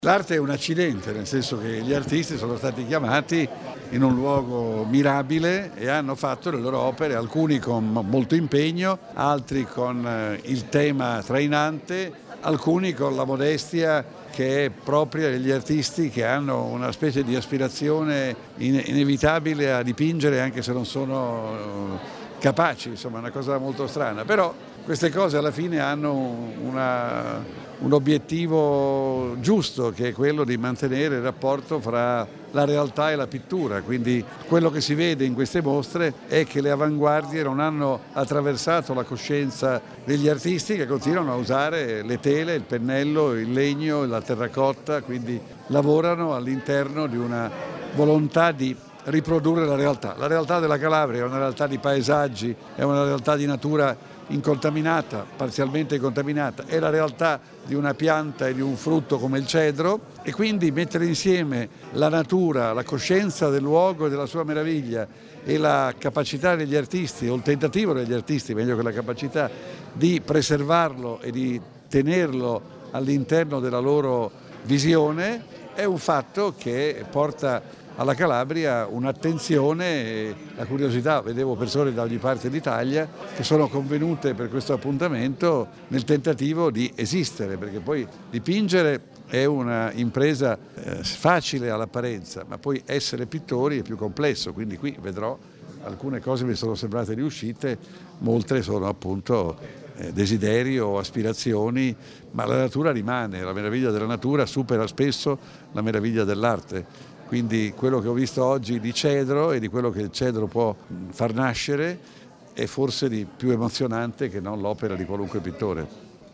Il sottosegretario alla Cultura e critico d’arte Vittorio Sgarbi ospite al Museo del Cedro di Santa Maria del Cedro per la mostra “Protagonisti del Tempo”.